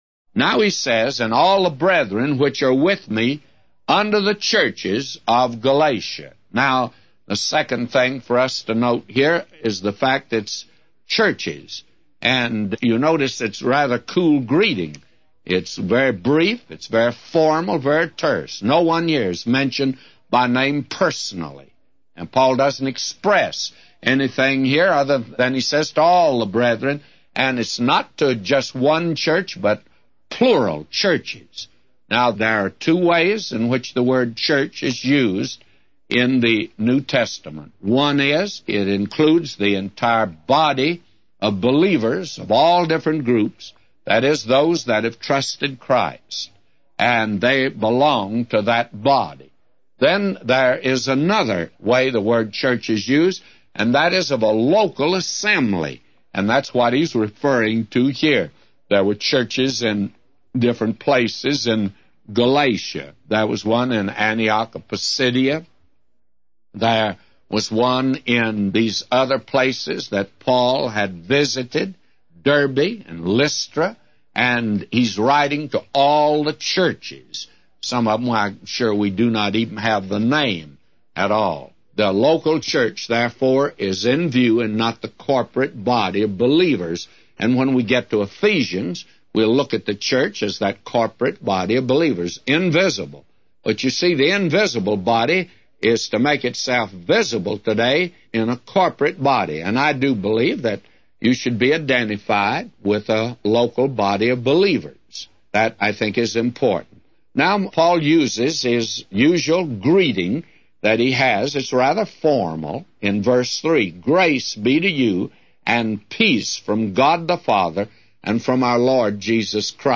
A Commentary By J Vernon MCgee For Galatians 1:2-999